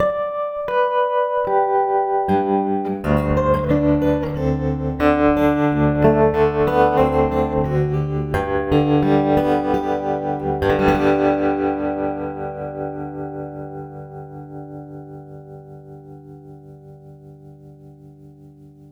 The guitar is a Martin OM-18GE with a K&K Pure Western pickup.
Something on the wild side, F4-3,
Clean Cowboy.
Clean-Cowboy.wav